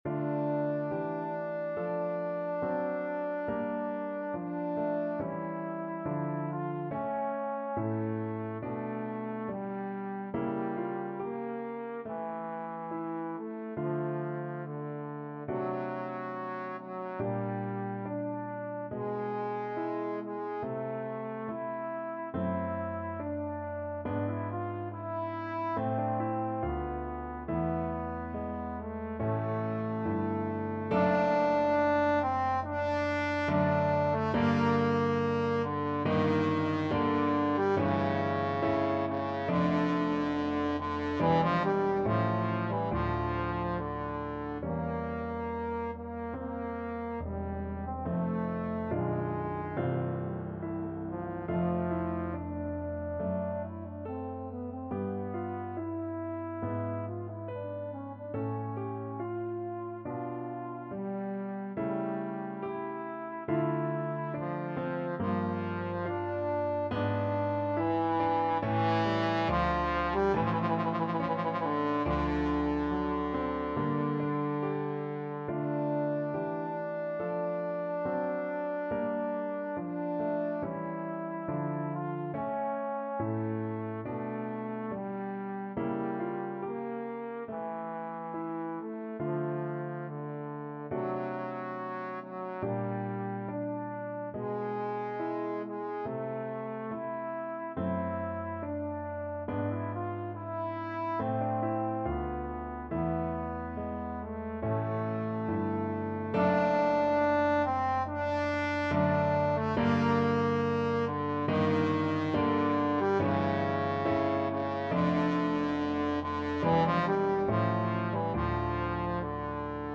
Trombone version
4/4 (View more 4/4 Music)
Adagio =70